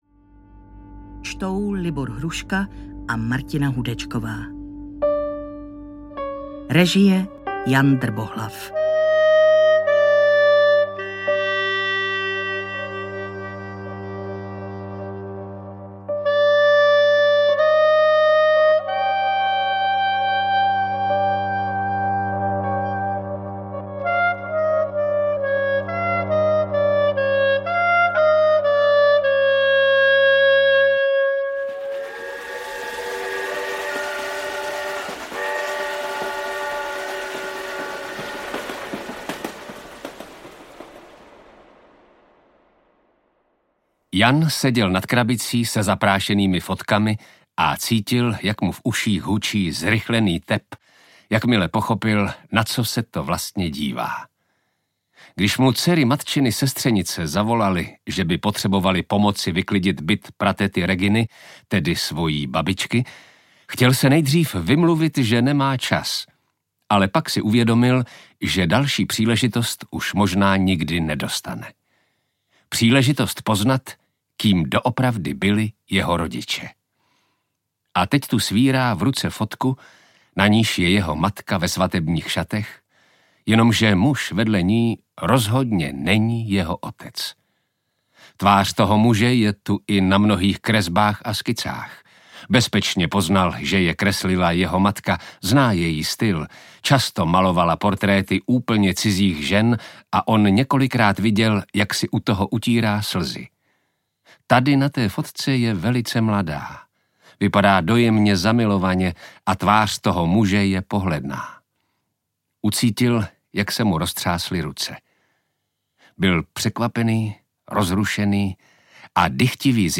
Svátek hada audiokniha
Ukázka z knihy